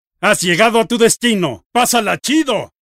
Para celebrar el estreno de la divertida película de Ted 2, Waze –la app de navegación social en tiempo real que obtiene información de las personas que la integran– ofrece a los conductores la opción de poder escuchar las instrucciones de navegación en la voz del malhablado y ultra simpático Ted.